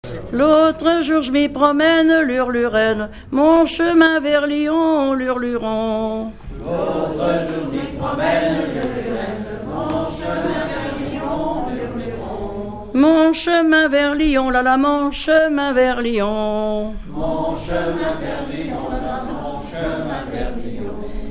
Mémoires et Patrimoines vivants - RaddO est une base de données d'archives iconographiques et sonores.
ronde : grand'danse
Pièce musicale inédite